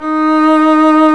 Index of /90_sSampleCDs/Roland L-CD702/VOL-1/STR_Violin 1 vb/STR_Vln1 _ marc
STR VLN MT07.wav